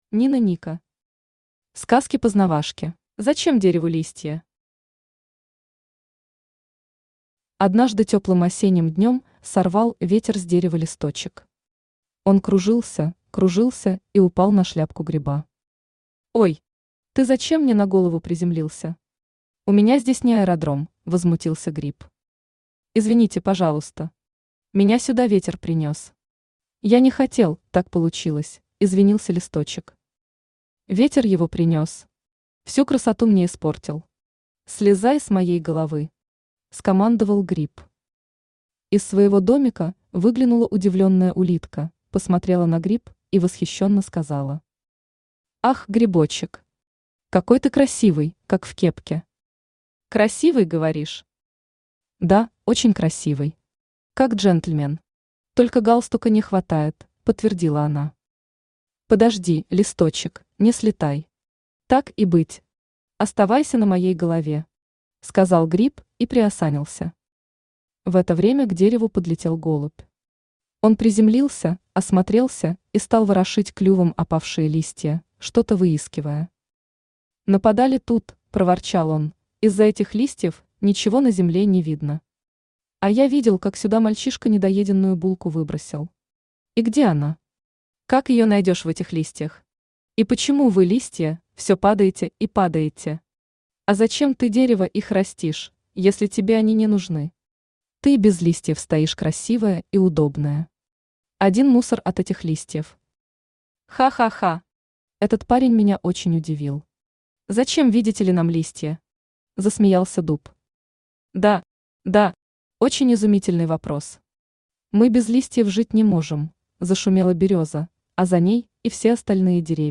Аудиокнига Сказки-познавашки | Библиотека аудиокниг